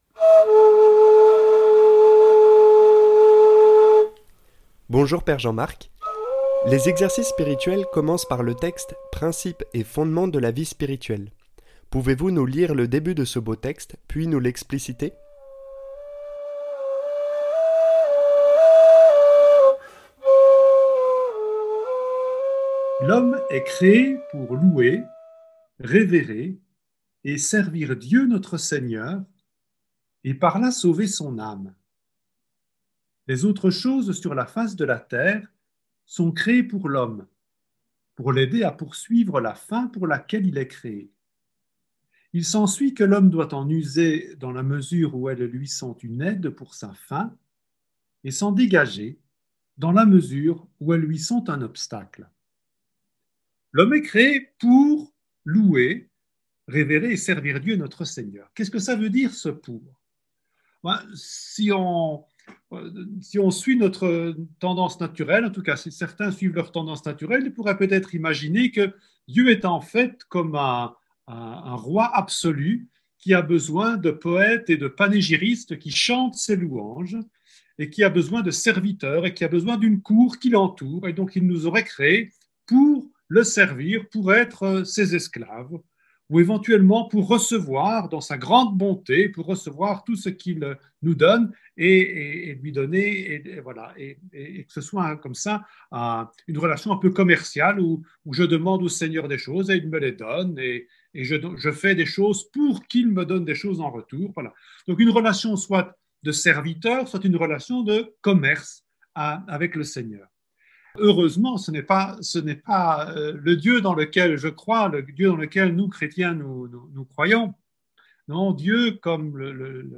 > Transcription de l’interview